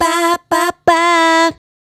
Bah Ba Bahh 120-E.wav